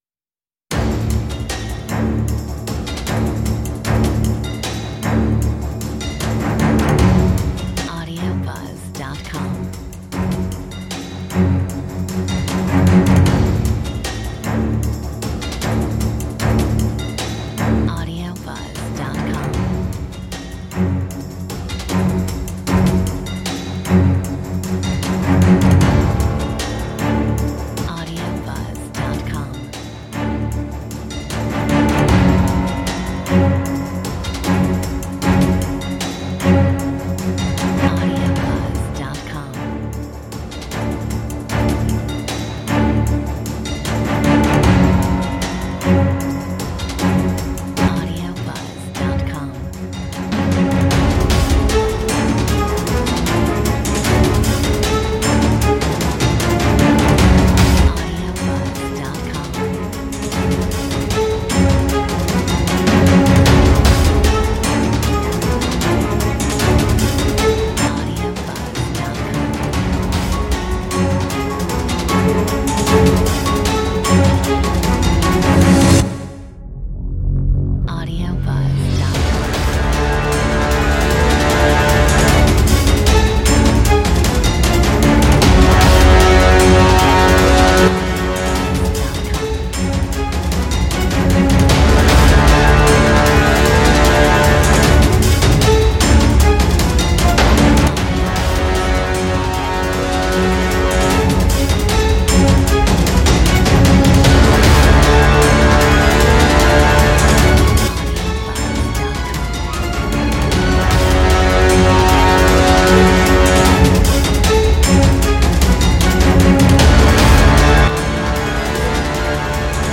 Metronome 77